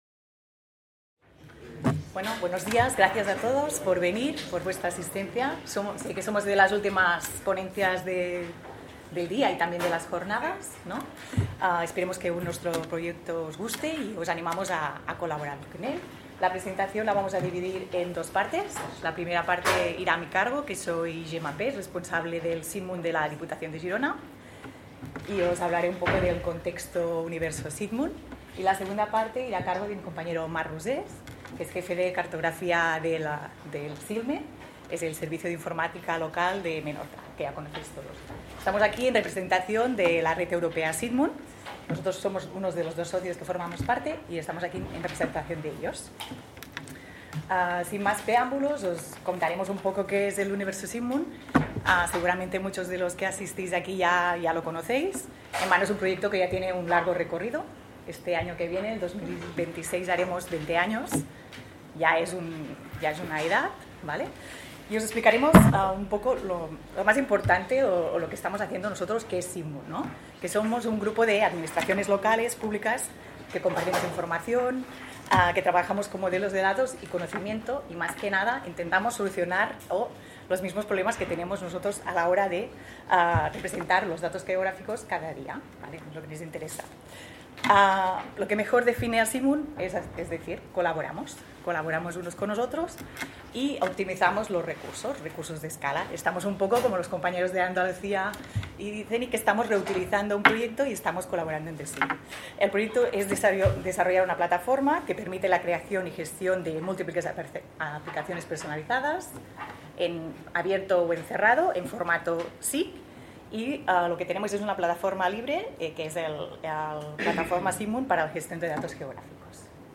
en el marc de les 18enes Jornades de SIG Lliure 2025 organitzades pel SIGTE de la Universitat de Girona. Ens presenten el projecte SITMUN que és un projecte de software lliure que permet gestionar múltiples aplicacions des d'una única plataforma d'administració  This document is licensed under a Creative Commons: Attribution – Non commercial – Share alike (by-nc-sa) Show full item record